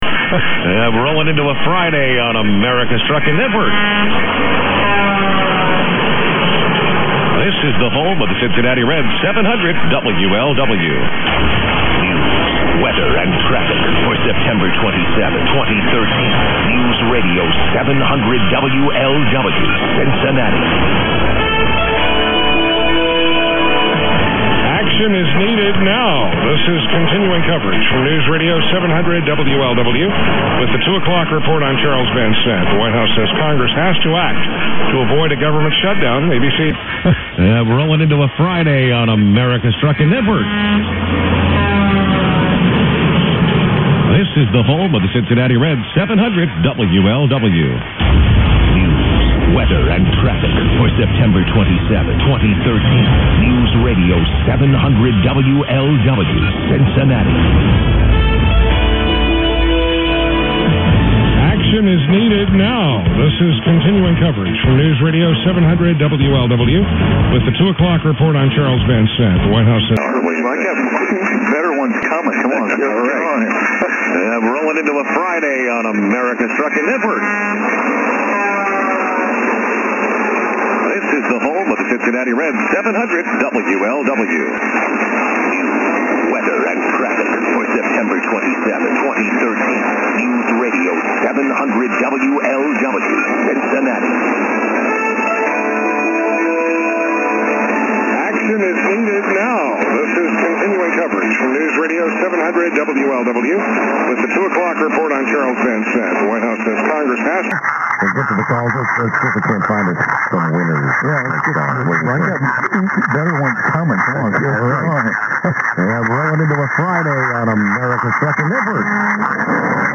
This recording is of WLW 700, a common station in Europe, but it is listened to on USB only here due to heavy BBC signals from 693.
And the audio on SSB and AM sounds like it's being fed down an old 70's phone line, similar in quality to some of those Spanish FM stations that used to come through during the summer openings.
130927_0600_700_wlw_perseus_ssb_am_hdsdr_ssb_am.mp3